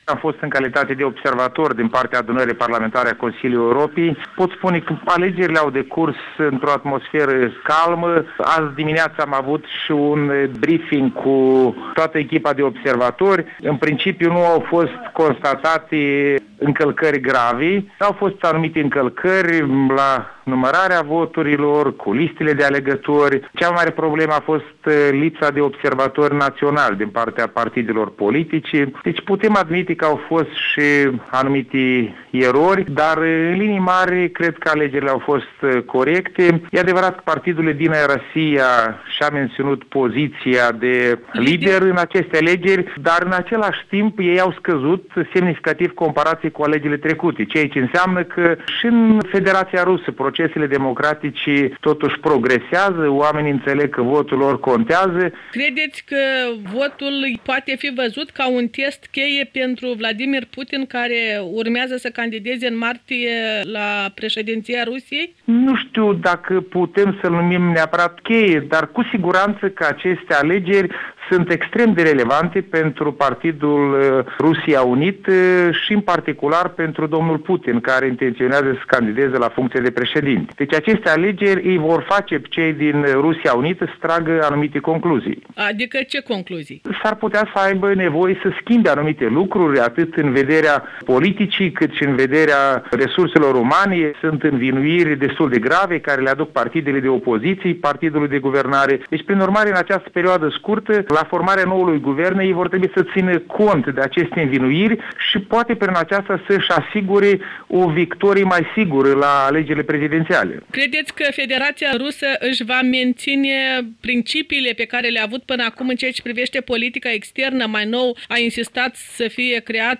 Un interviu cu observatorul parlamentar Valeriu Ghilețchi despre alegerile din Rusia